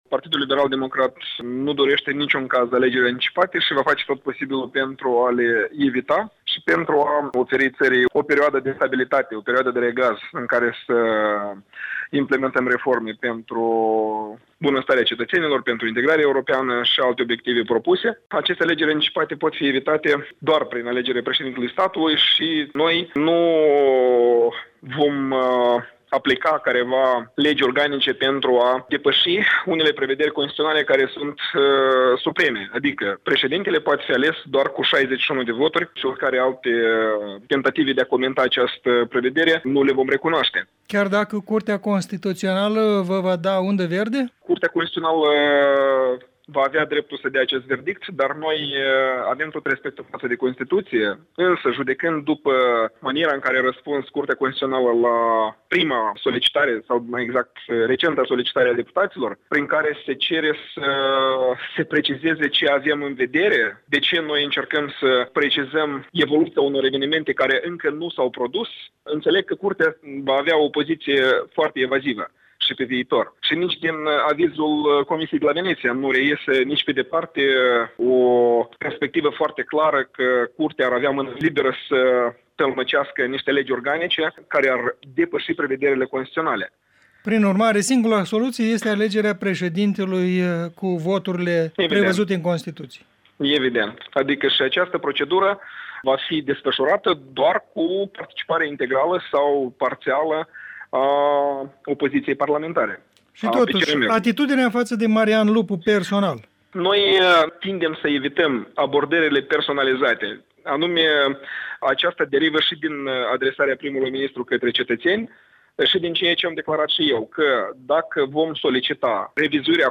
Un interviu cu deputatul PLDM Valeriu Streleţ